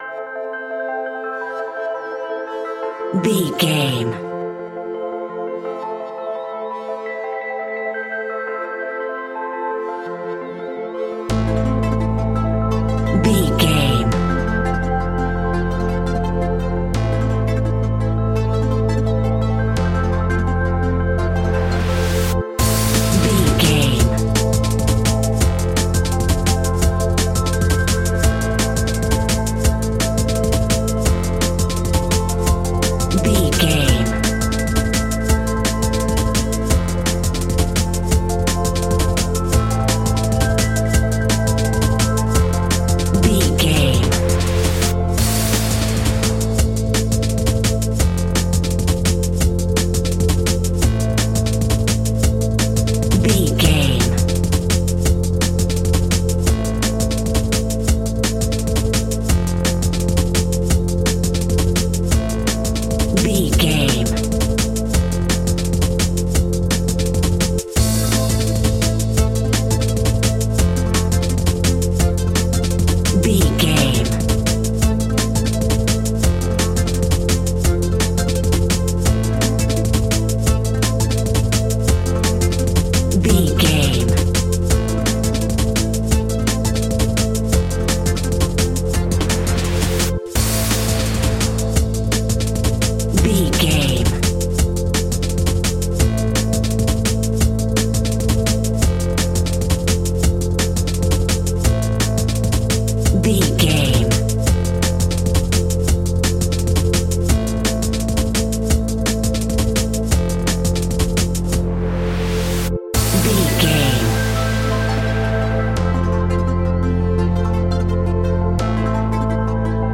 Aeolian/Minor
Fast
aggressive
groovy
futuristic
frantic
drum machine
synthesiser
darkstep
sub bass
Neurofunk
synth leads
synth bass